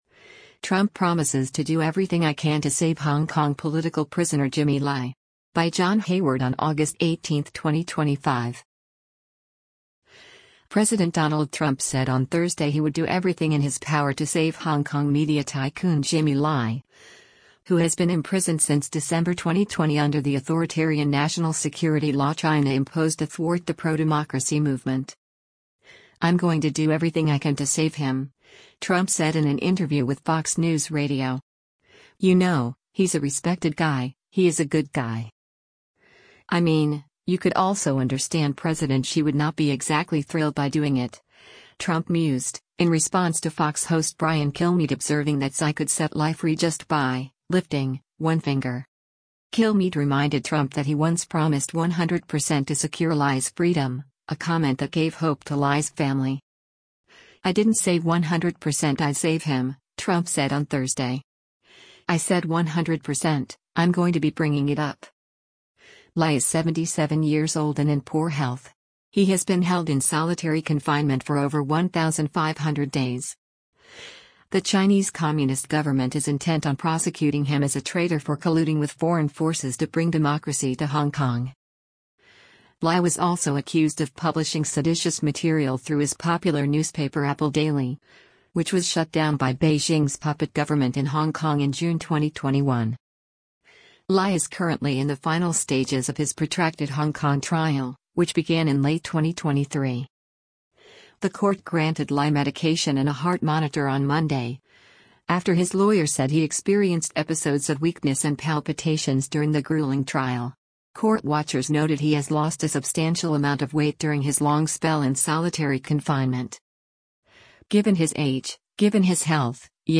“I’m going to do everything I can to save him,” Trump said in an interview with Fox News Radio.